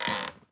chair_frame_metal_creak_squeak_02.wav